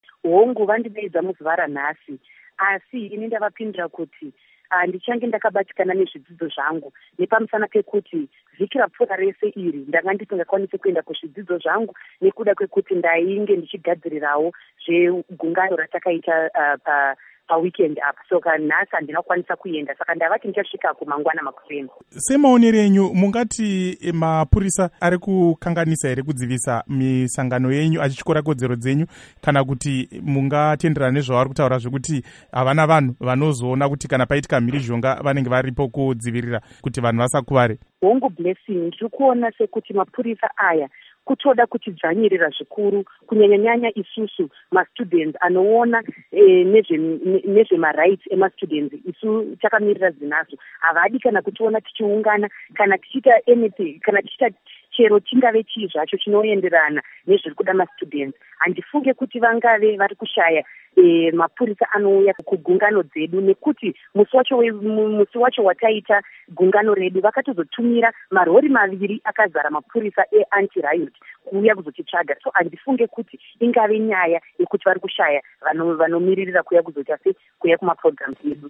Hurukuro naMuzvare Joana Mamombe